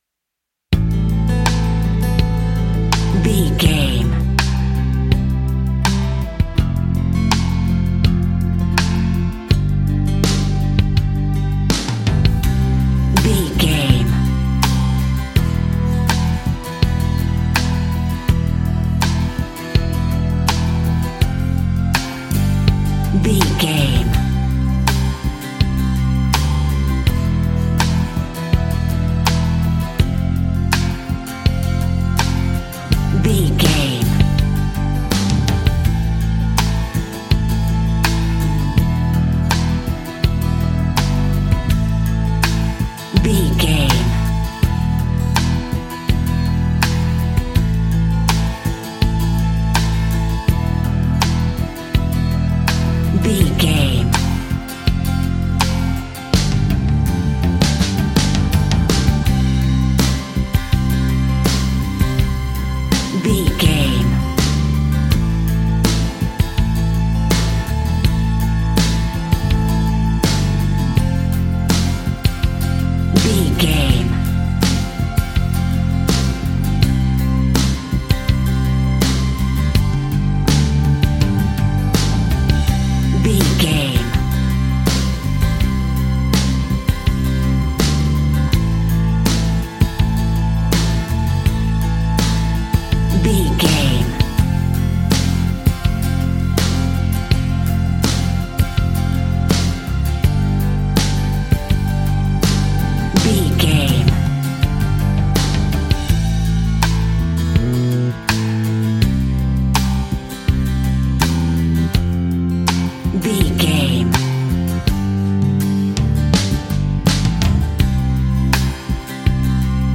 Ionian/Major
romantic
sweet
happy
acoustic guitar
bass guitar
drums